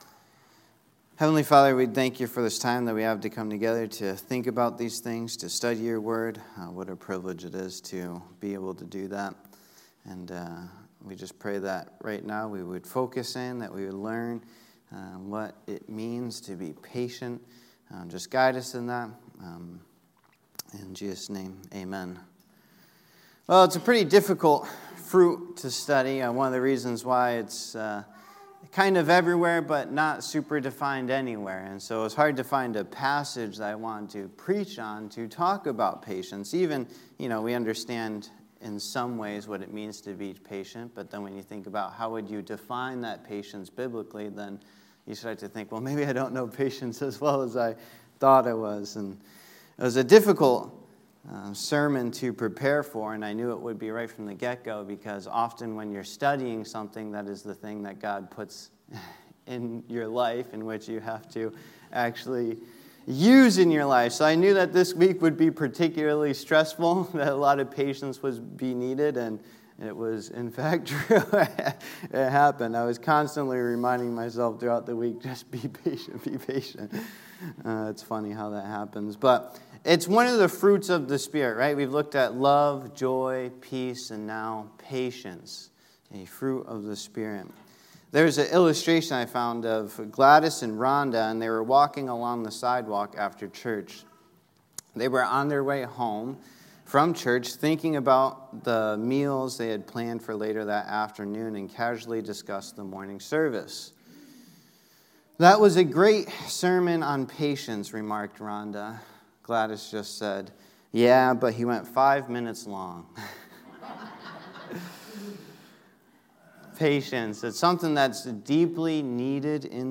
Sermons | Ellington Baptist Church